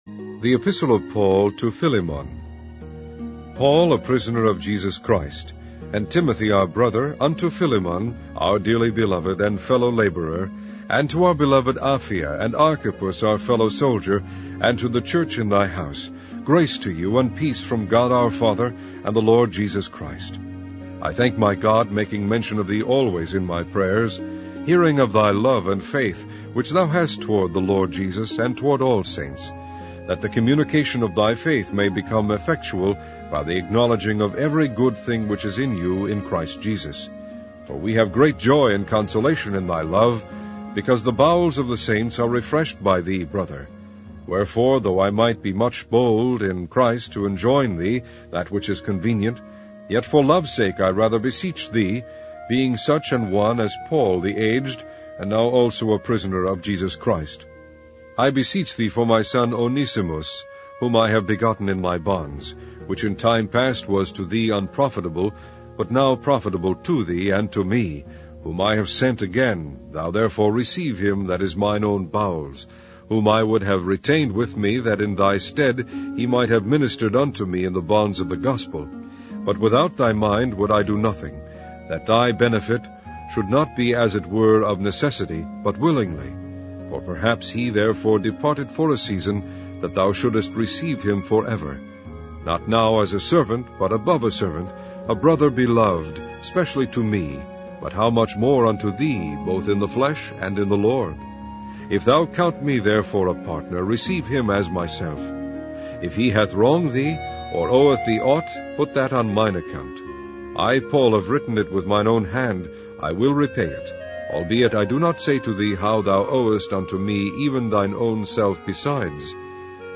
Primitive Baptist Digital Library - Online Audio Bible - King James Version - Philemon